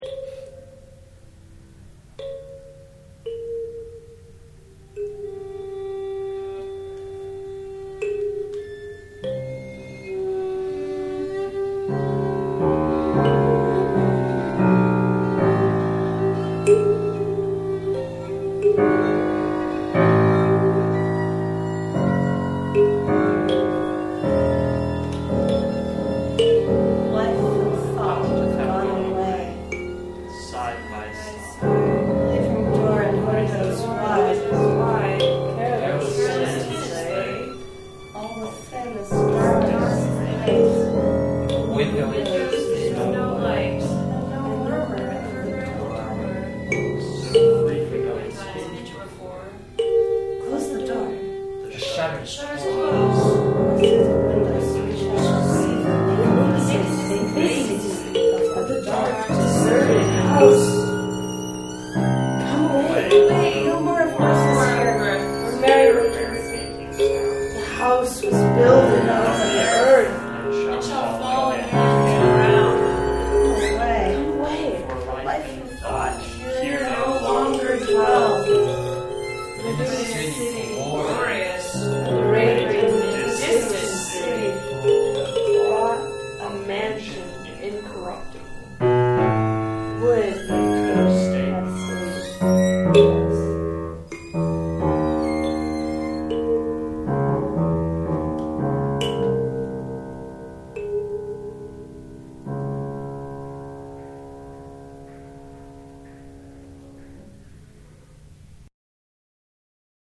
These files haven’t been mastered yet, so there’s some peaking and a few volume problems, but if you want to get a quick-and-dirty MP3 sense of what we recorded yesterday, have a listen.